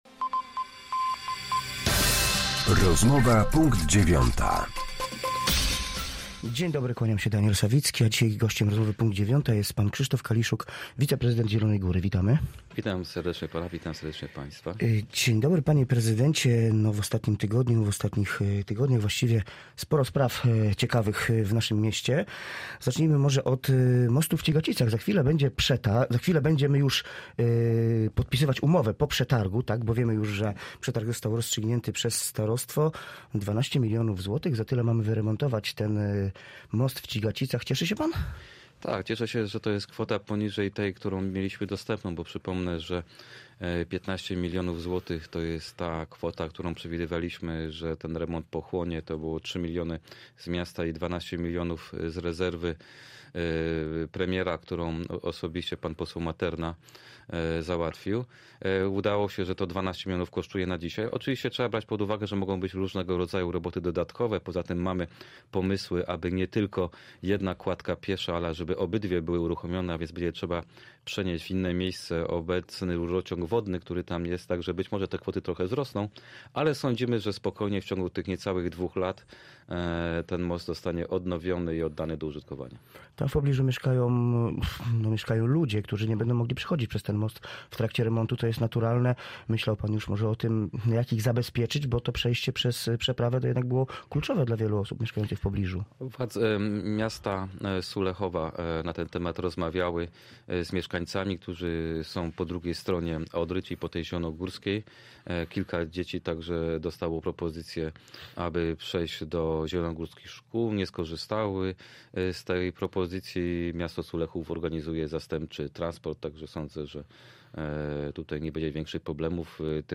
Z wiceprezydentem miasta Zielona Góra